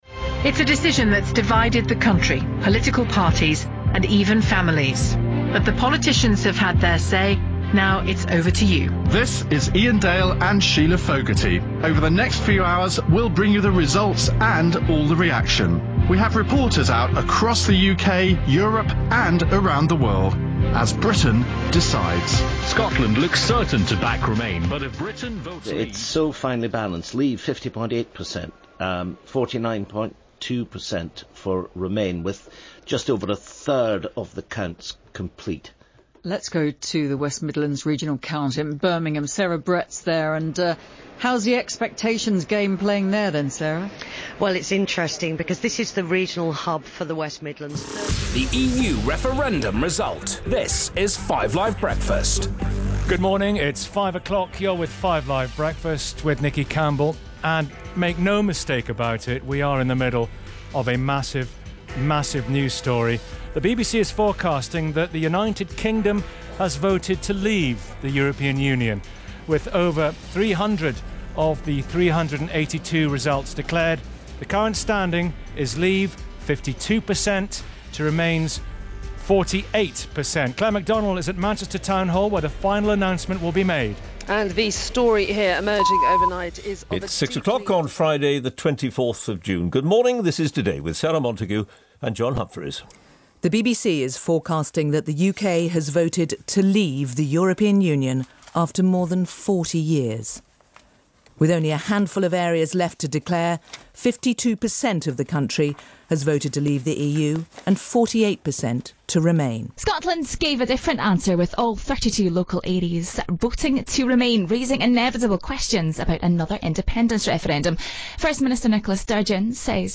A flavour of how radio around the British Isles reflected the historic vote on the future of its position within the EU.